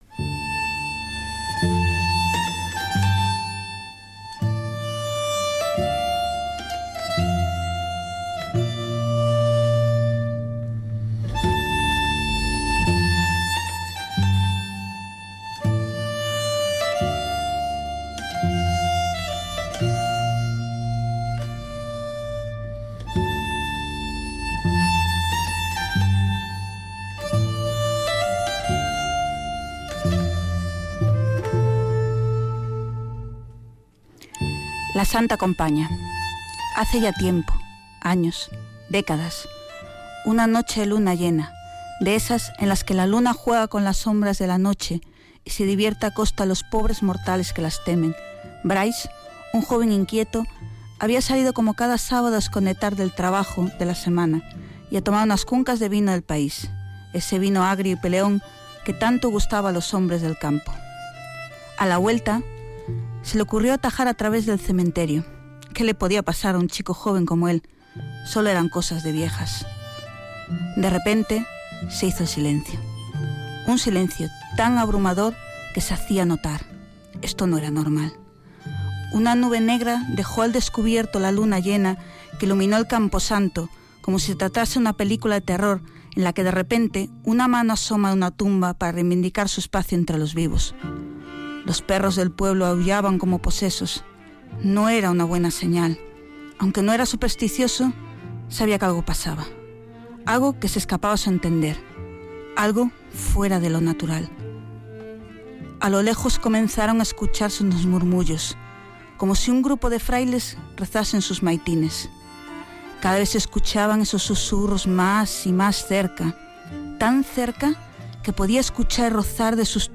Lectura de relatos goticos